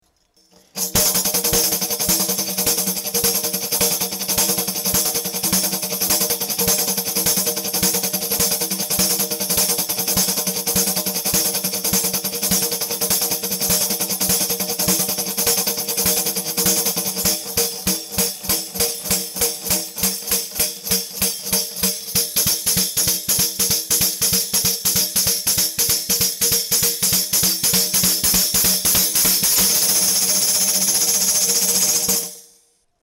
Звуки тамбурина
Звук игры на тамбурине